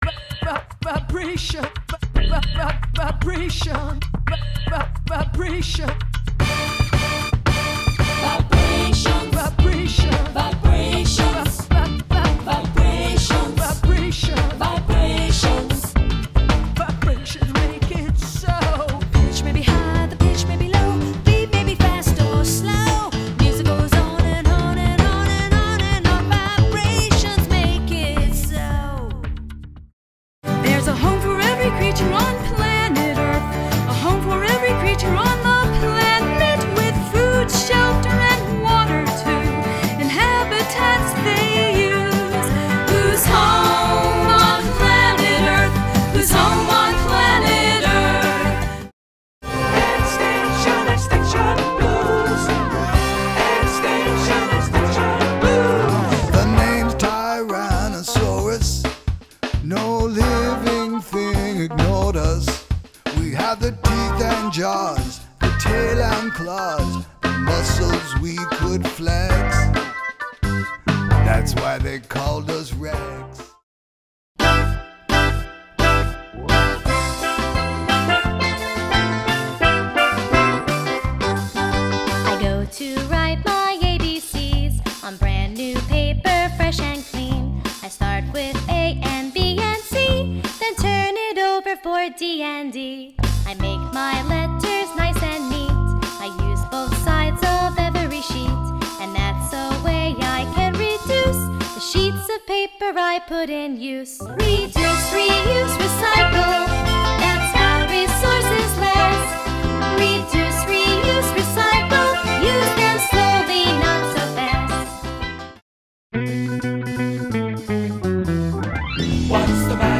A Musical Extravaganza of Sciencey Songs for Kids
Forty-five minutes of high-energy, interactive songs. Dinosaurs. Stars. Weather. Creatures. Matter. And so much more.
Foot-stomping rhythms. Sing-along lyrics.
Pre-recorded backup audio with amazing production values. One lead vocalist. Two live musicians (who double as backup vocalists).
A five-minute demo of selected songs from the show: